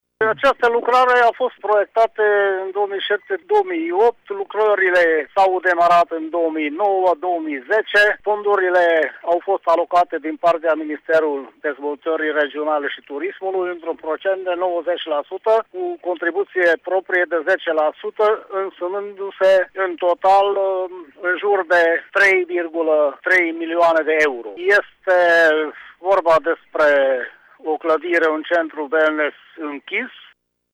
Primarul localităţii Praid, Alexandru Bokor, a declarat pentru RTM că lucrarea a fost proiectată încă din 2007, iar 90% din investiţie a fost suportată de Ministerul Turismului: